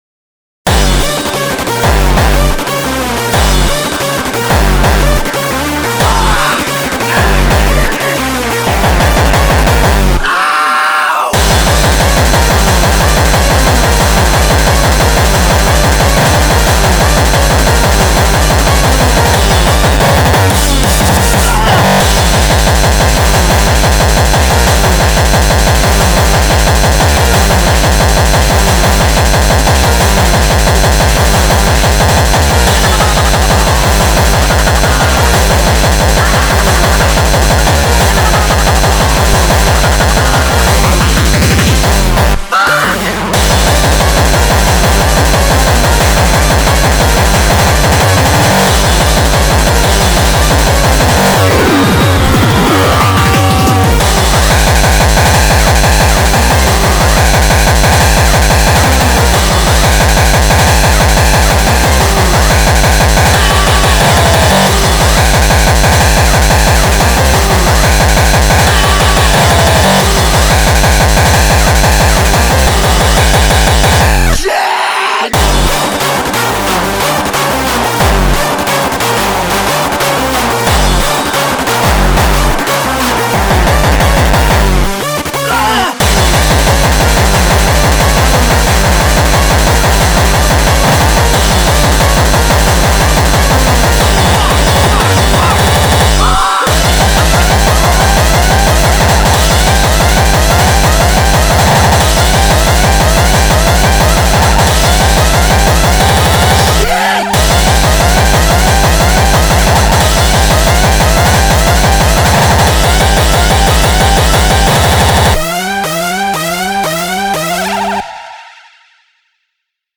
BPM360
Audio QualityMusic Cut
Genre: Speedcore